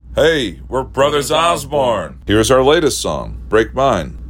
LINER Brothers Osborne (Break Mine) 3